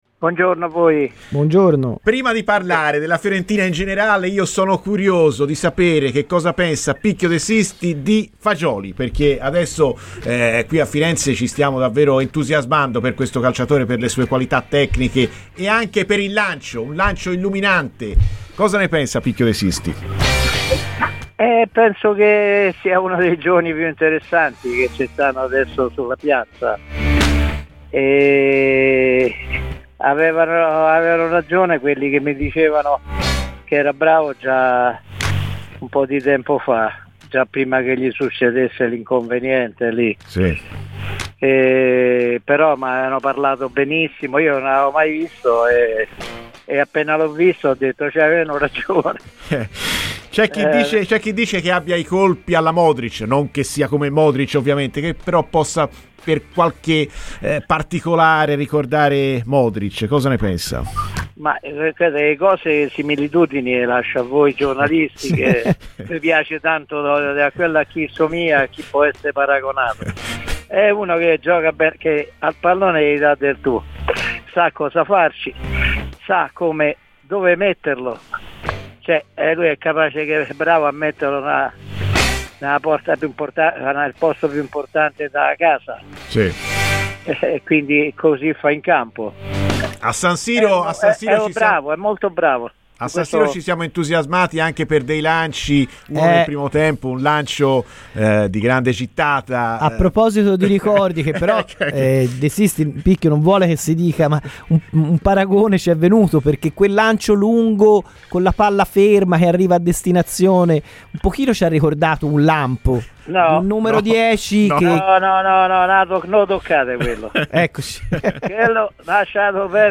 Giancarlo De Sisti, ex calciatore viola è intervenuto a Radio FirenzeViola durante la trasmissione "Viola Amore Mio" per parlare dell'attuale situazione della Fiorentina: "Fagioli è uno dei giovani più interessanti, avevano ragione coloro che mi dicevano che era forte.